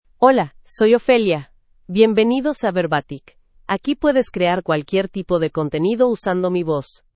FemaleSpanish (United States)
OpheliaFemale Spanish AI voice
Ophelia is a female AI voice for Spanish (United States).
Voice sample
Ophelia delivers clear pronunciation with authentic United States Spanish intonation, making your content sound professionally produced.